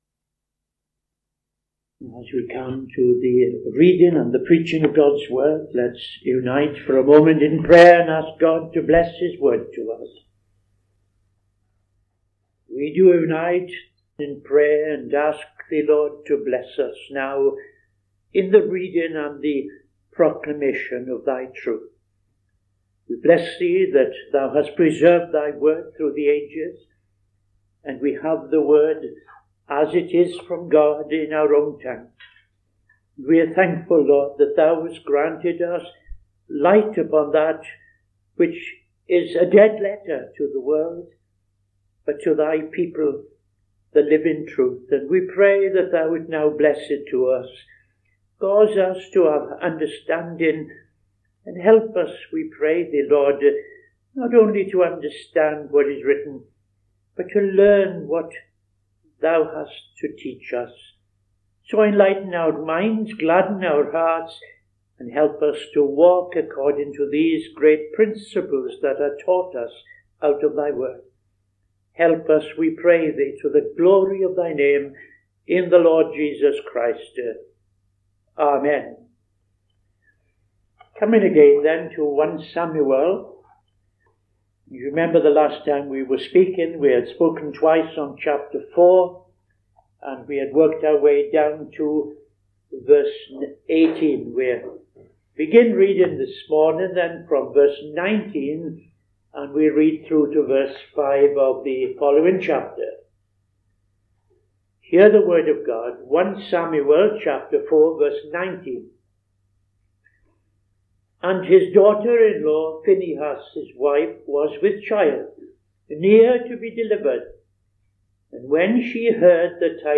Bible Study - TFCChurch